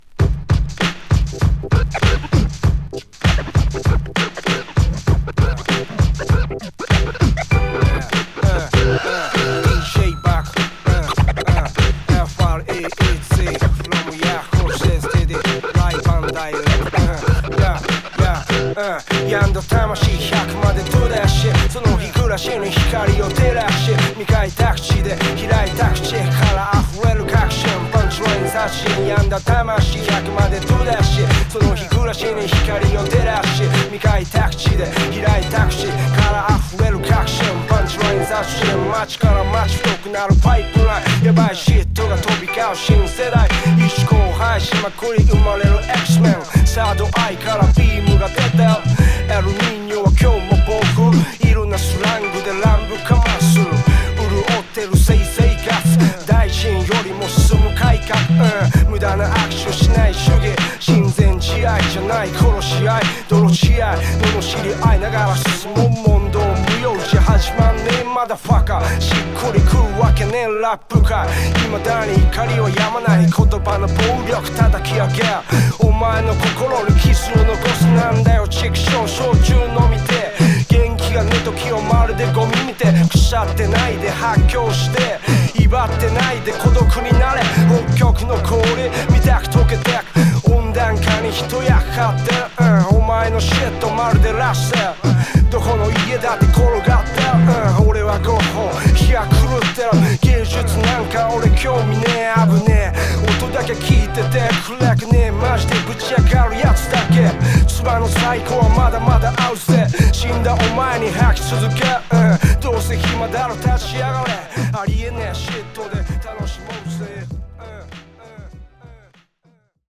男気炸裂のマイク・リレーが繰り広げられるSide-A!!